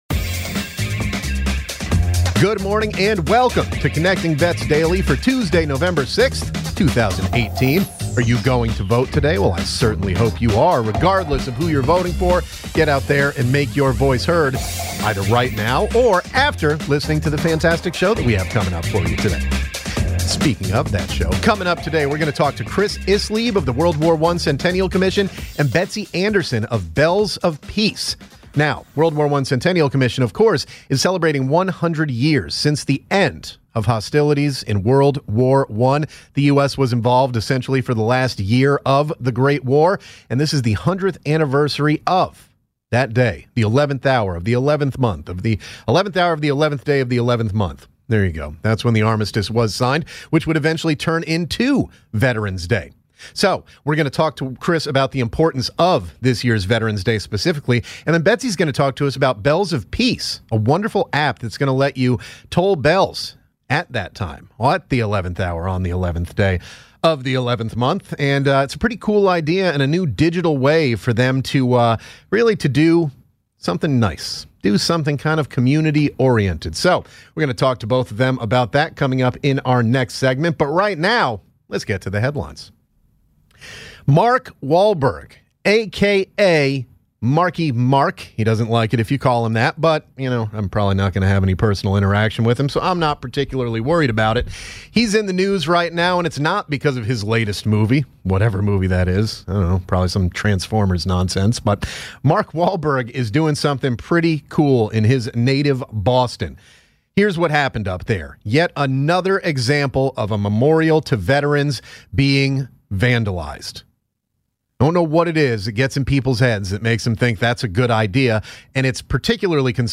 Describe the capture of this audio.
Bells of Peace join the show in studio to talk about upcoming events for the World War I Centennial.